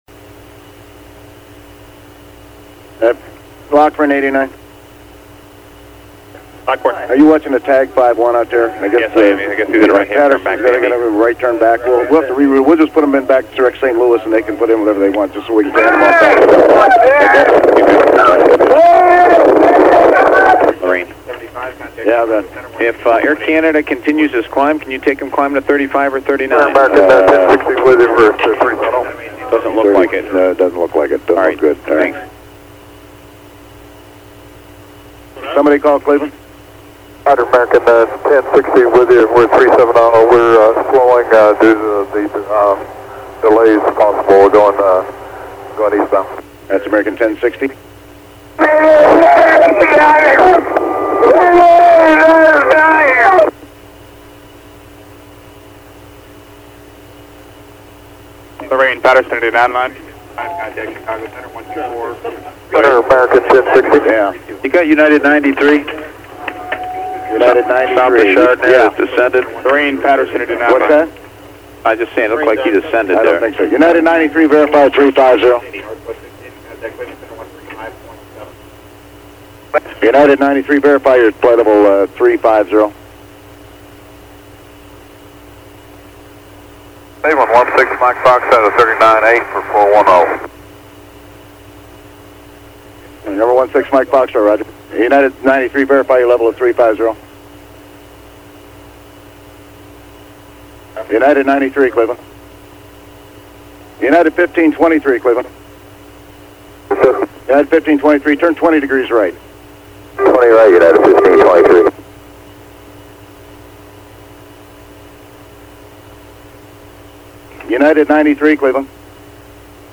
258 United 93, registrazione audio ATC (irruzione dei dirottatori in cabina).